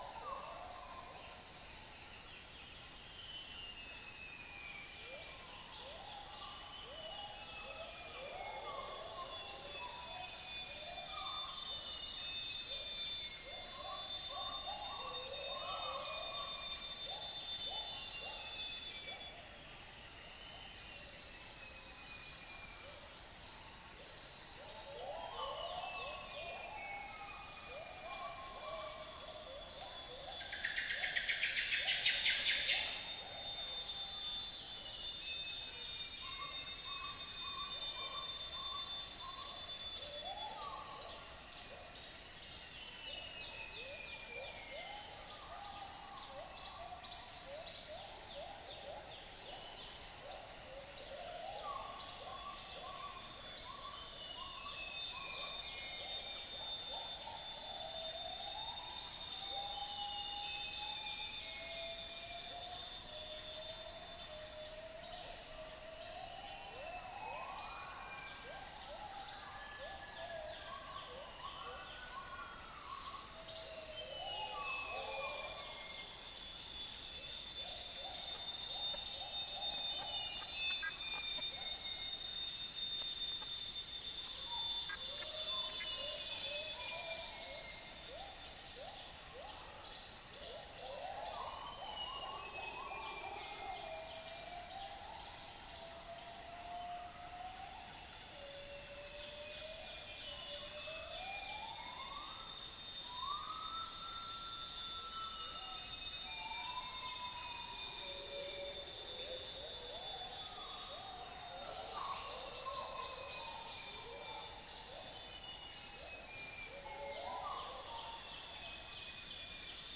Dicrurus paradiseus
Trichastoma malaccense
Malacopteron magnirostre
unknown bird